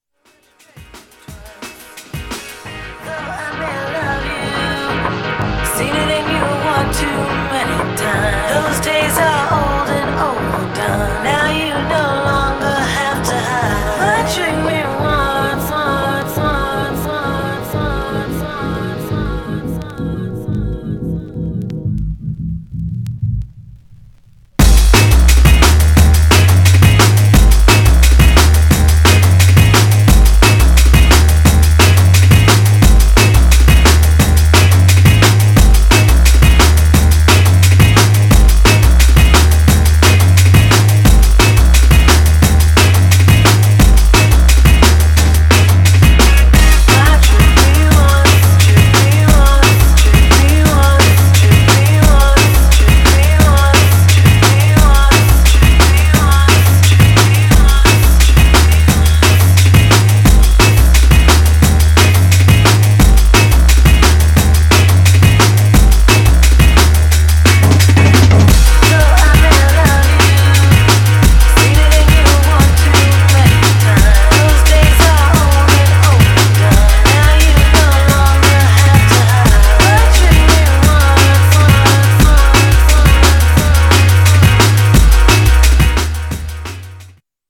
Styl: Drum'n'bass, Breaks/Breakbeat